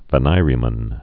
(və-nīrē-mən, -nîrē-)